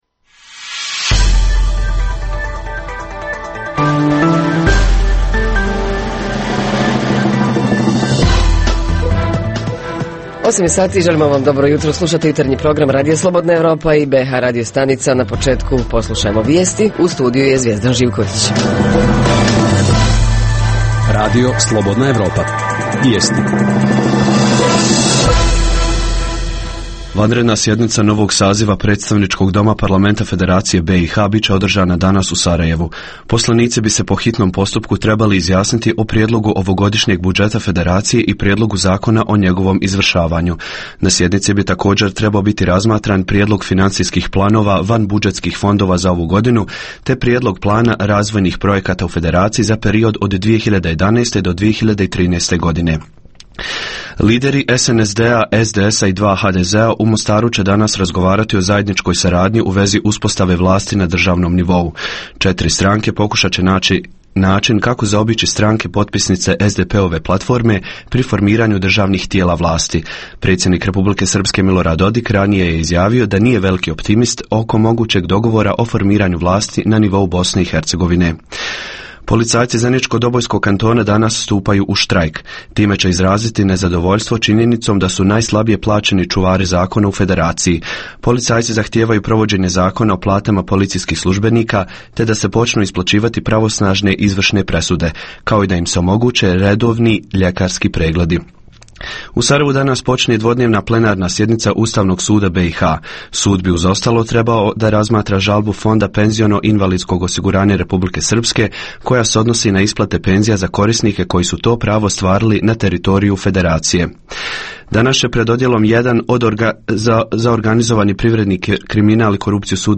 Podsticaji za zapošljavanje - ima li kakvih programa čiji je cilj podsticati zapošljavanje? Reporteri iz cijele BiH javljaju o najaktuelnijim događajima u njihovim sredinama.